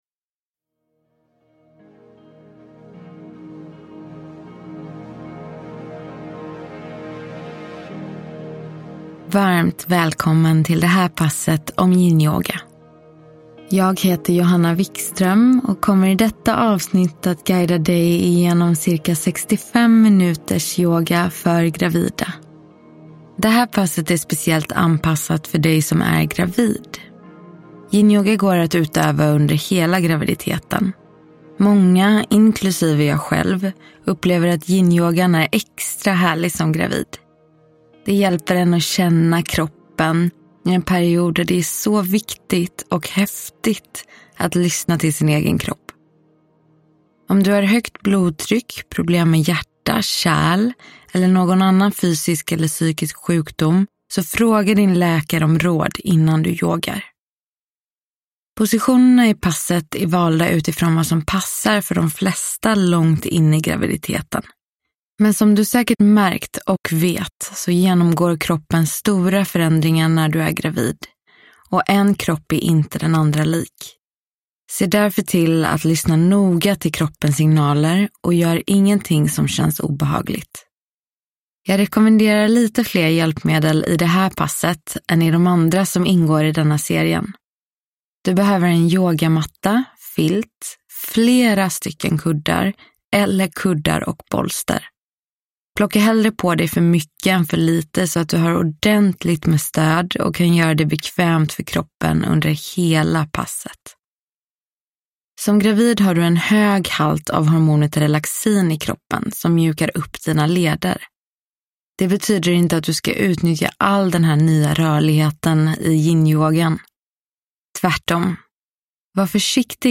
Yinyoga - Pass för gravida – Ljudbok